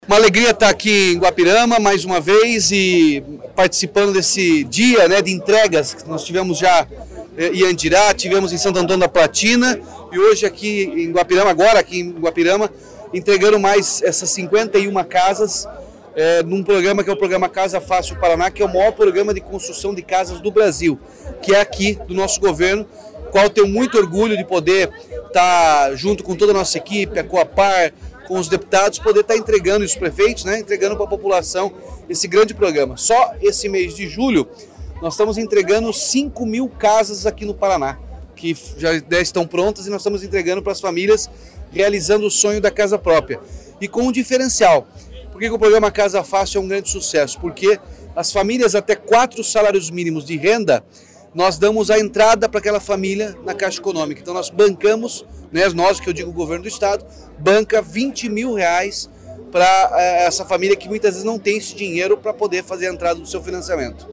Sonora do governador Ratinho Junior sobre a entrega de apartamentos em Guapirama